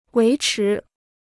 维持 (wéi chí): to keep; to maintain.